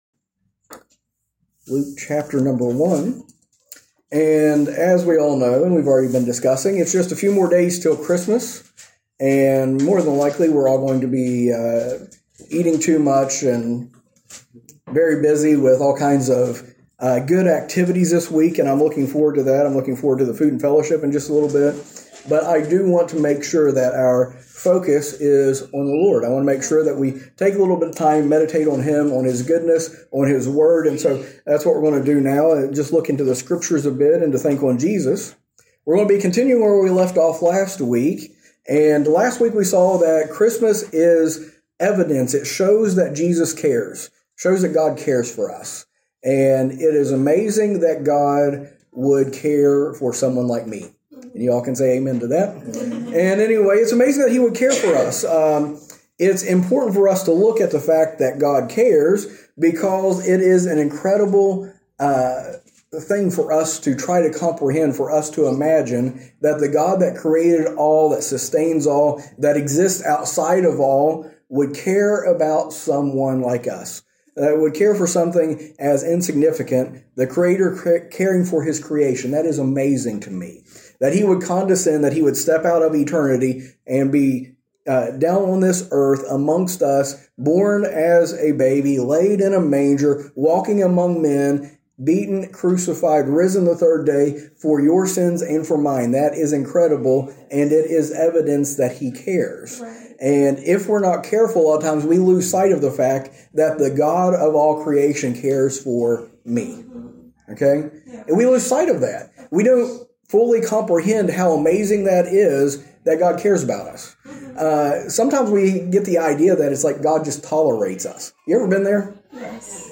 A message from the series "Series Breaks."